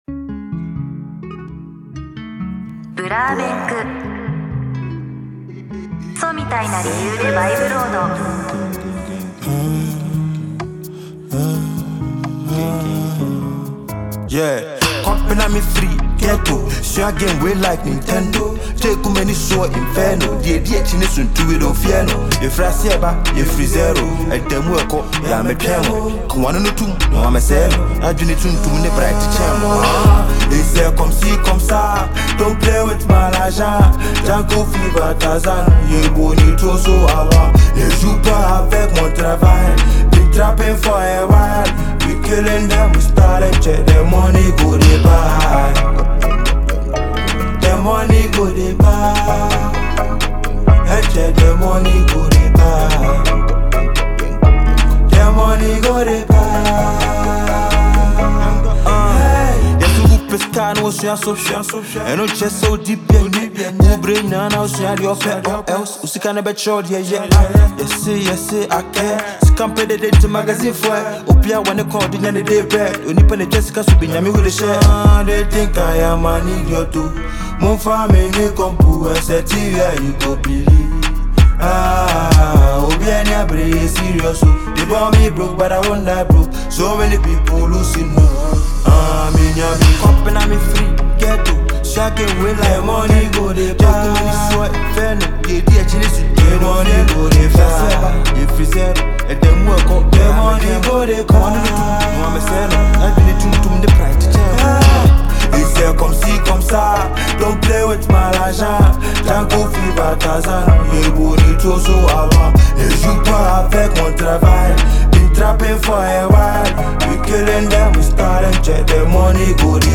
Asakaa rapper